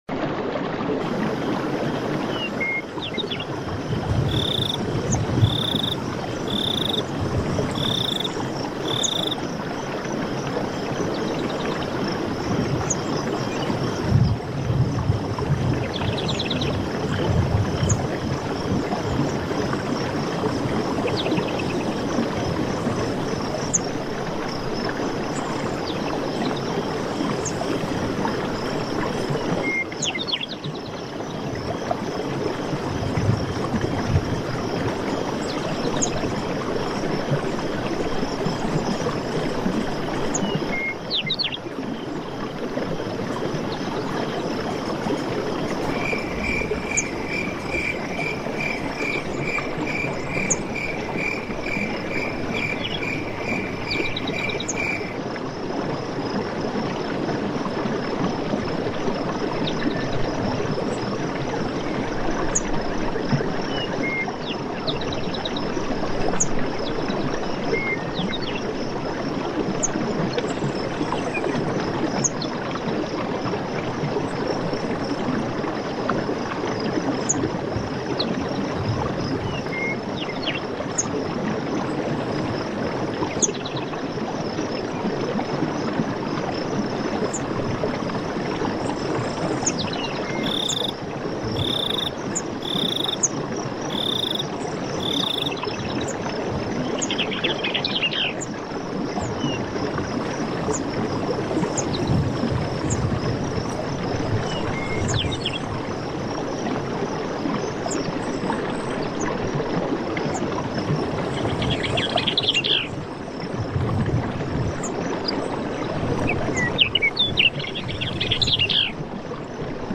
ERDE-GESANG: Wasser-Felsen-Lied mit singenden Tropfen